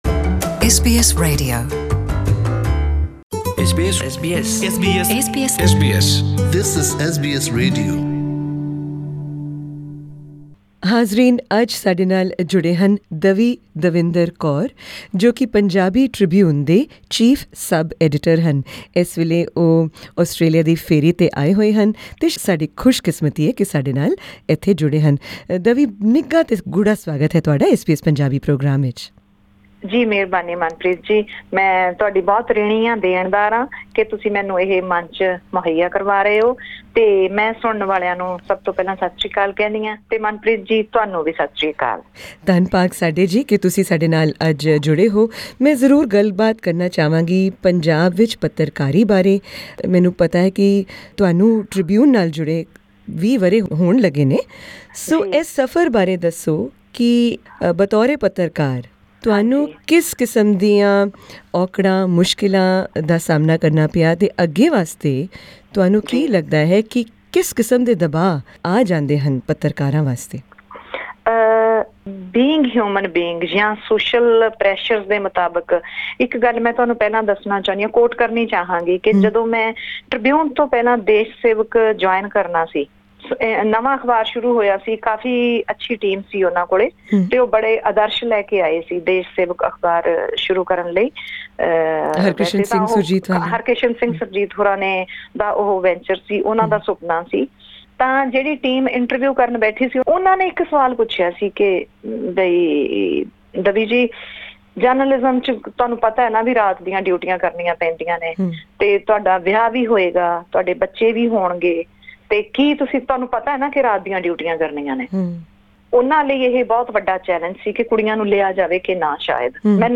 In a candid conversation about India's media landscape